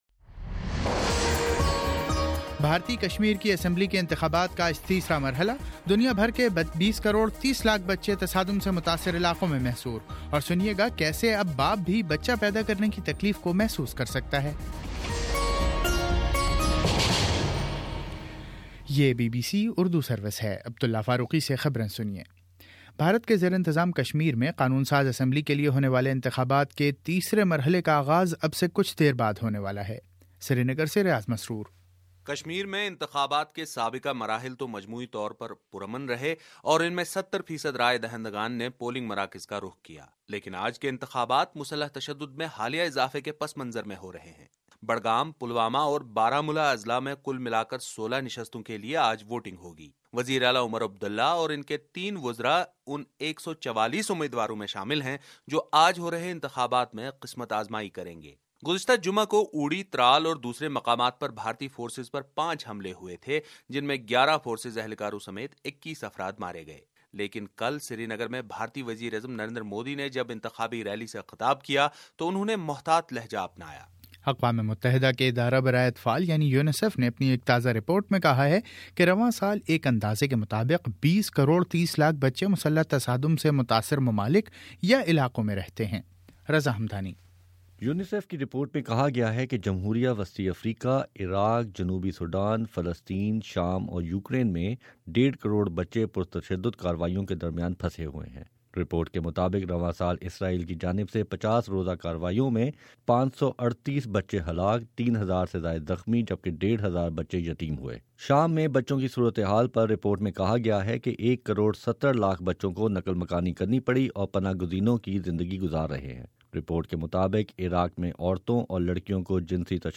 دسمبر09: صبح نو بجے کا نیوز بُلیٹن